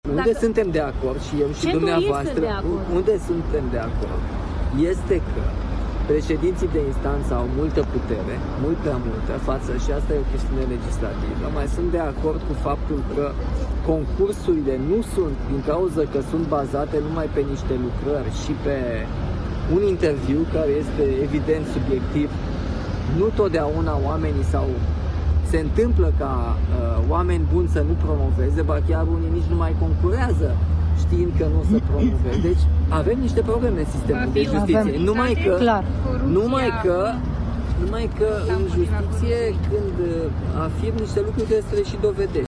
Președintele Nicușor Dan a stat de vorbă cu aceștia înainte de evenimentul oficial.